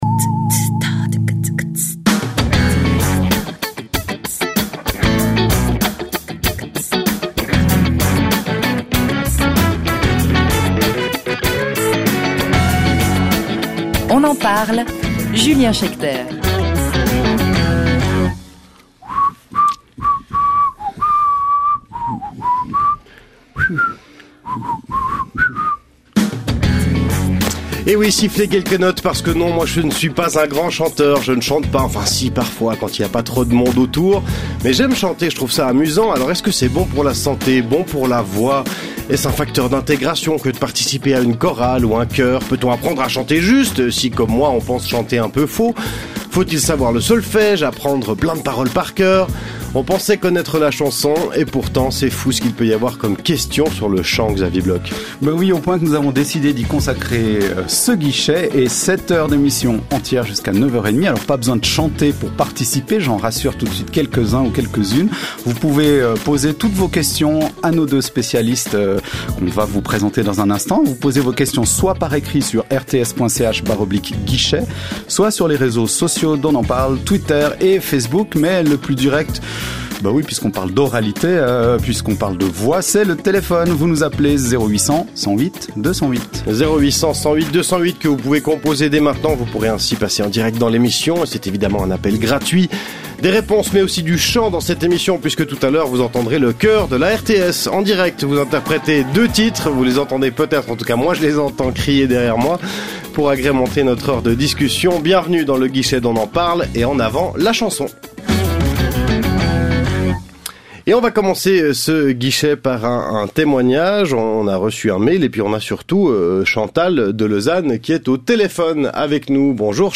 Des spécialistes vous répondent en direct sur La Première de 8h35 à 9h30.